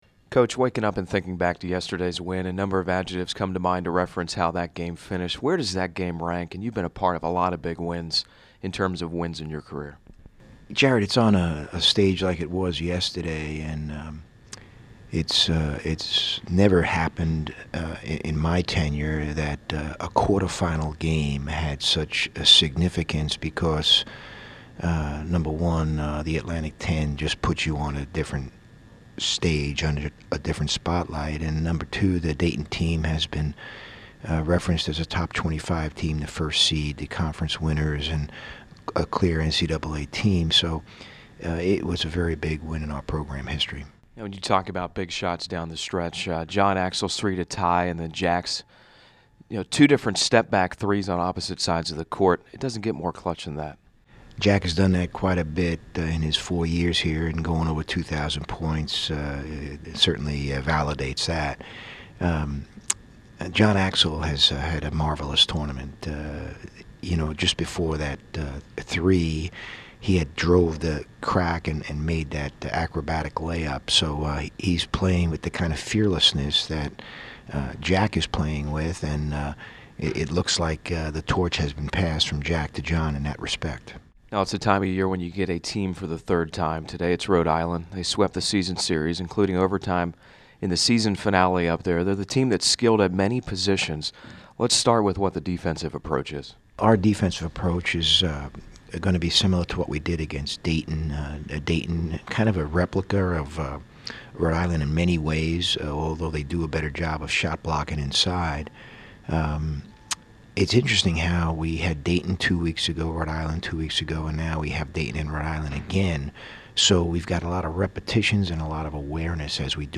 Pregame Radio Interview (Bob McKillop)